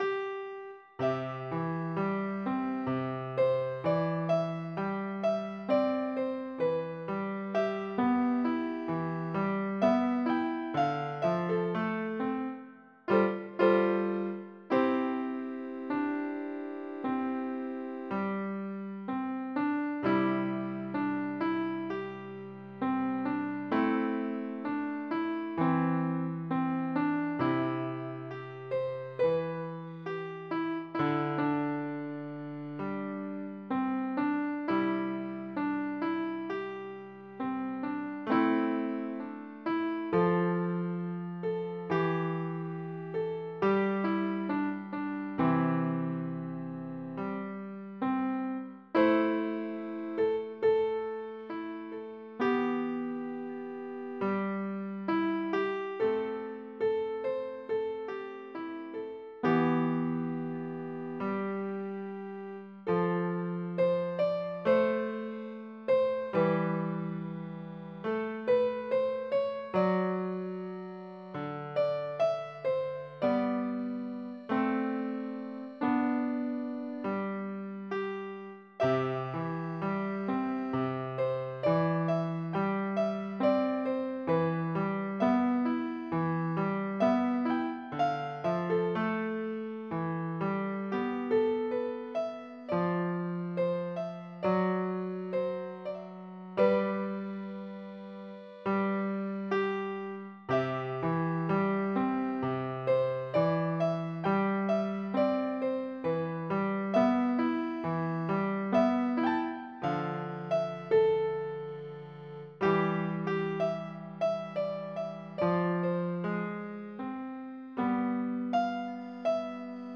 練習2曲目